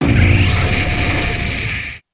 Grito de Reptalada.ogg
) Categoría:Gritos de Pokémon de la novena generación Categoría:Reptalada No puedes sobrescribir este archivo.
Grito_de_Reptalada.ogg